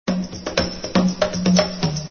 Index of /Habbo_Archive_V2_Wendigo/HabboStuff/Cokestudios Private Server/Cokemusic/src/Mixer Library/Latin Sounds
latinoOne_percCombo00.mp3